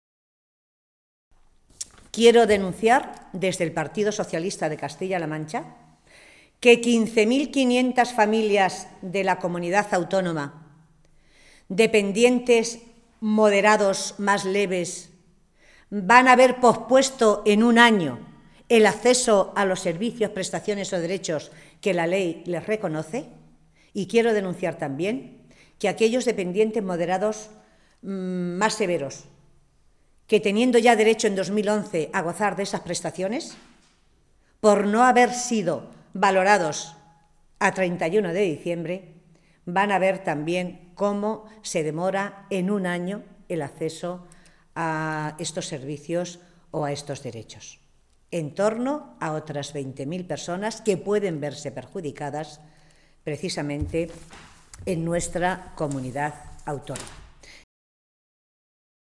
Matilde Valentín, portavoz en materia de Asuntos Sociales del Grupo Parlamentario Socialista
Cortes de audio de la rueda de prensa